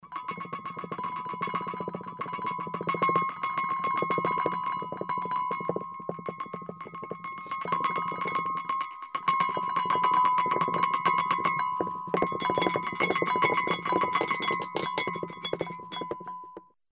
На этой странице собраны звуки землетрясений разной интенсивности: от глухих подземных толчков до разрушительных катаклизмов.
Дребезжание посуды во время землетрясения